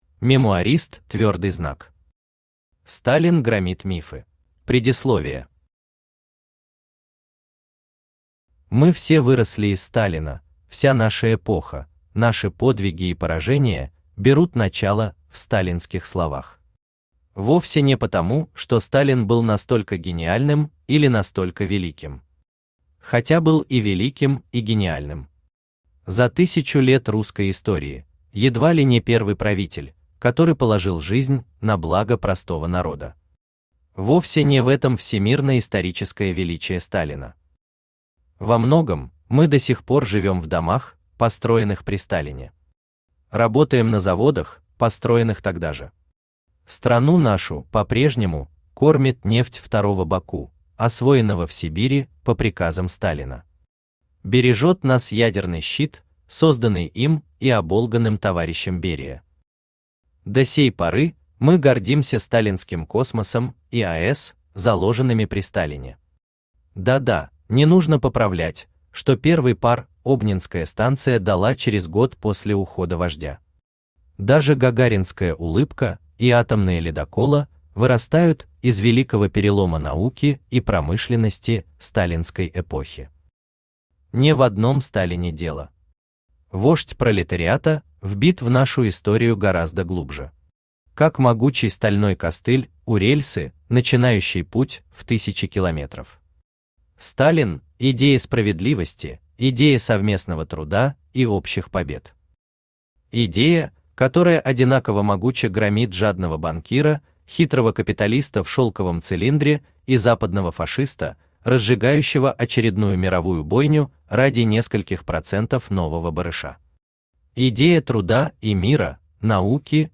Аудиокнига Сталин громит мифы | Библиотека аудиокниг
Aудиокнига Сталин громит мифы Автор МемуаристЪ Читает аудиокнигу Авточтец ЛитРес.